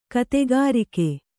♪ kategārike